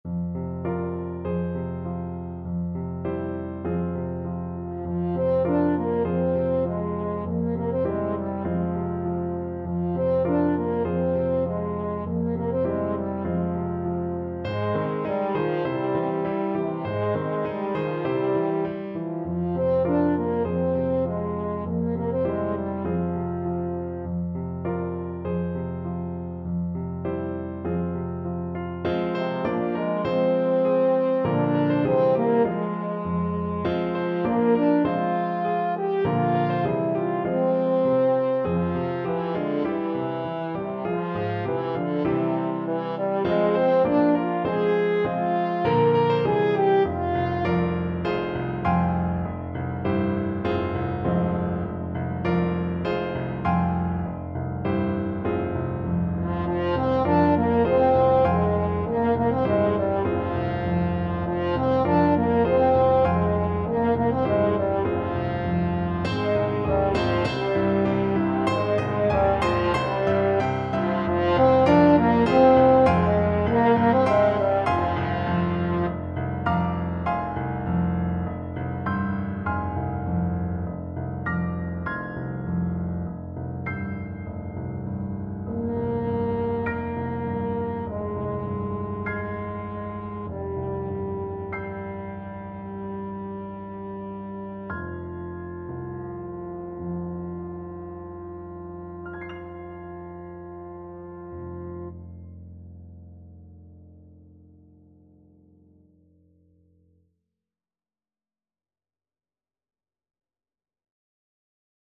French Horn
Traditional Music of unknown author.
2/4 (View more 2/4 Music)
F minor (Sounding Pitch) C minor (French Horn in F) (View more F minor Music for French Horn )
Moderato